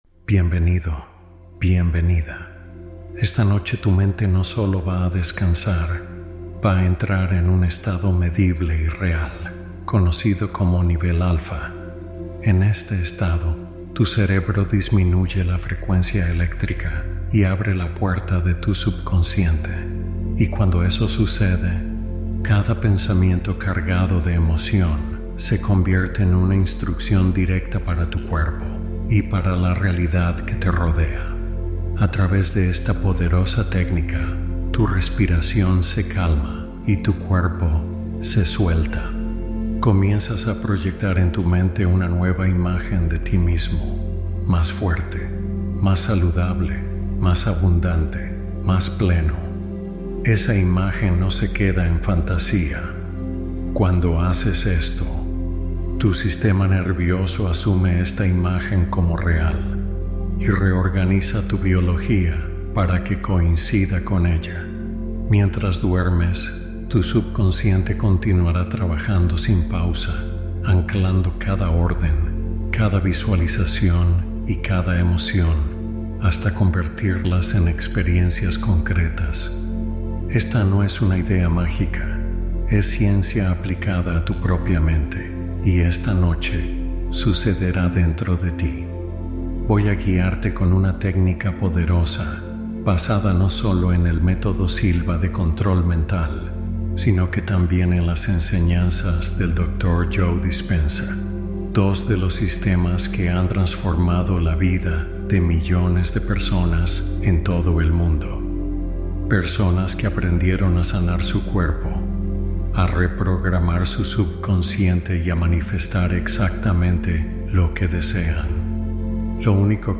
Hipnosis Para Lograr Todo Lo Que Deseas y Manifestar Mientras Duermes